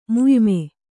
♪ muyme